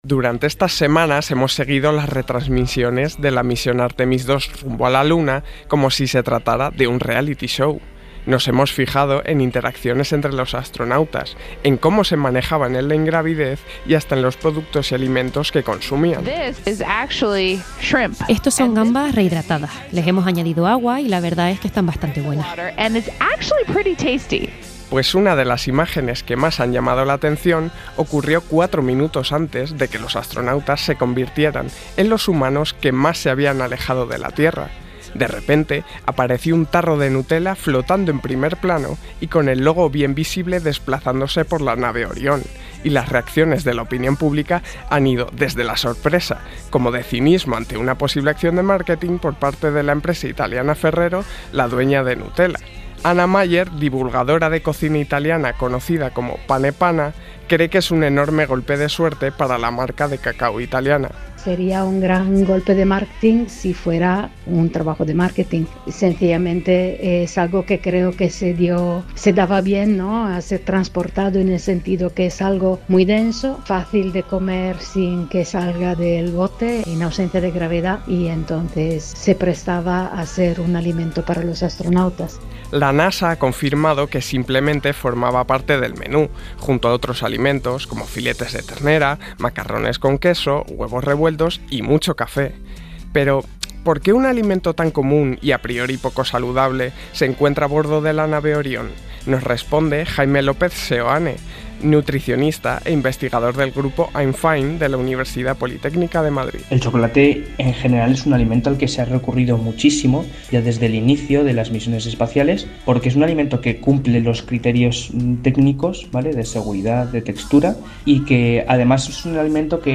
Reportaje | Comida espacial: de la Nutella al más allá – Podium Podcast